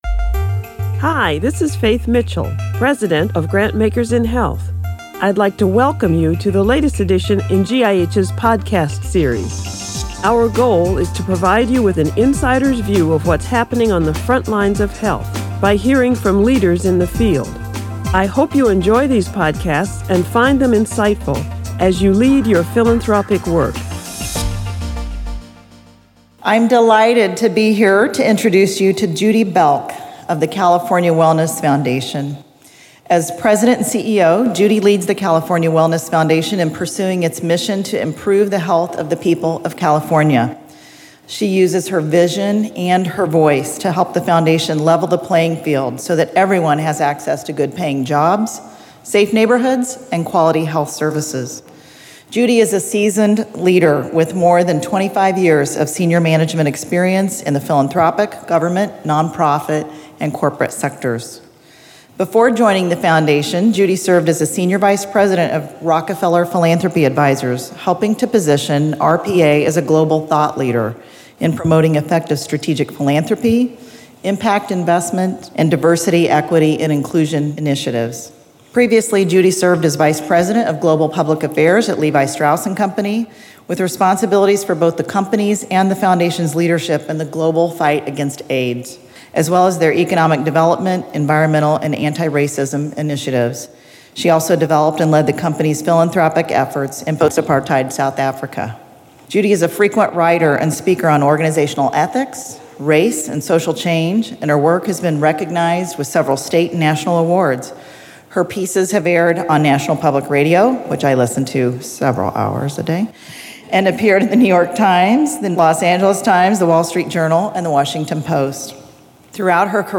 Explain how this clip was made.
2017 Annual Conference on Health Philanthropy Plenary Remarks